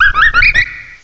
cry_not_victini.aif